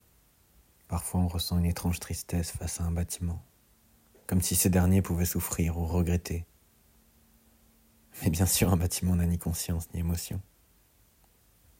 Bandes-son
Voix off Le Chai de Lescur